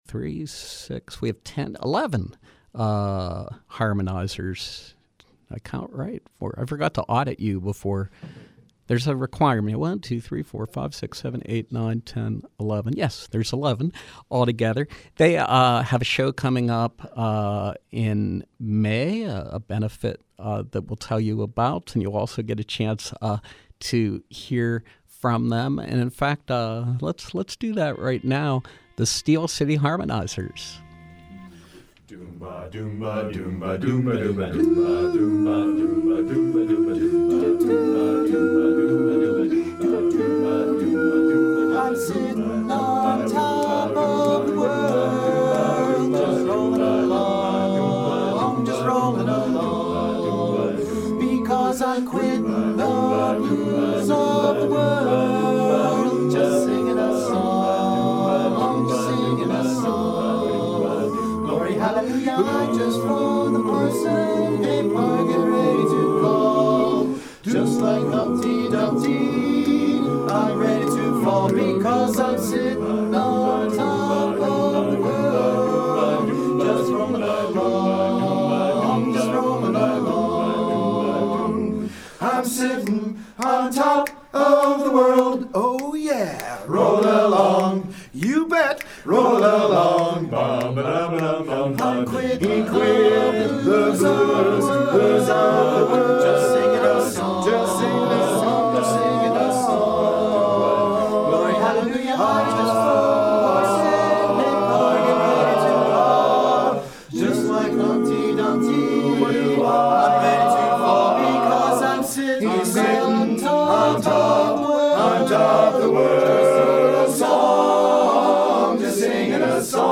Live Music: Steel City Harmonizers
From 03/18/2017: Four-part barbershop stylings with the Steel City Harmonizers.